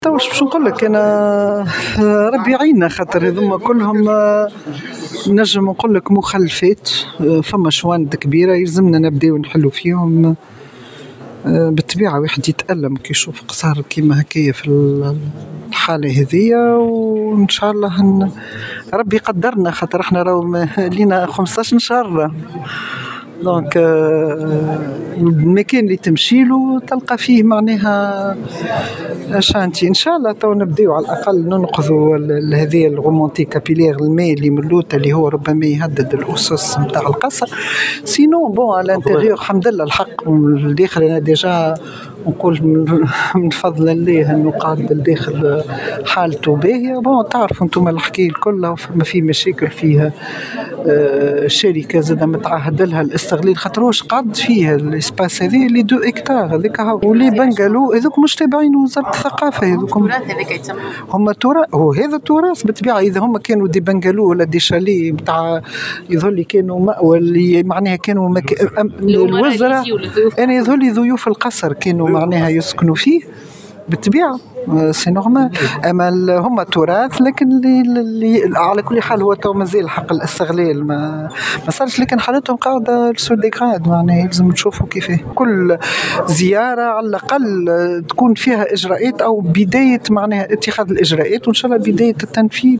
وعبّرت الوزيرة في تصريح لمراسل الجوهرة أف أم، عن أسفها وألمها للحالة المزرية التي وصل إليها هذا القصر، متعهدة بالشروع في عملية ترميمه، واتخاذ الإجراءات الكفيلة بإعادة تهيئته بداية بمنع تسرب المياه التي تهدد أسسه.